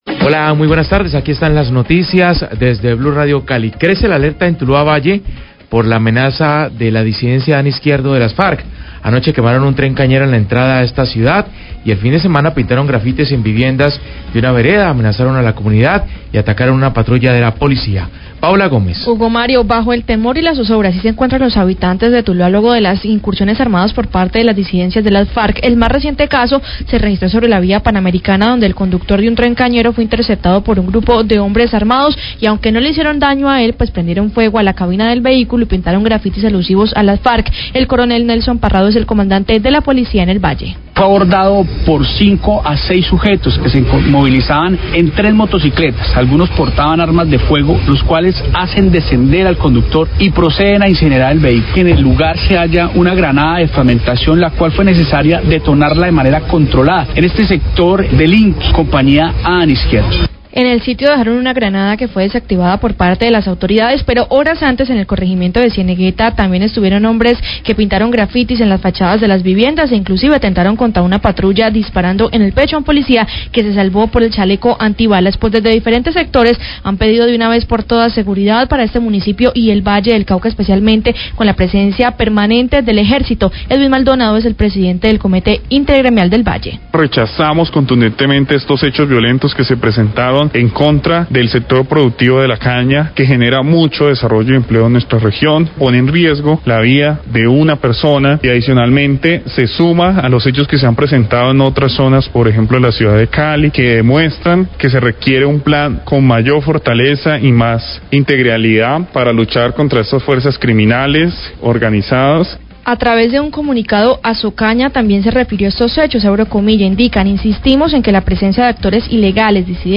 Radio
Declaraciones del coronel Nelson Parrado, comandante de la Policía Valle.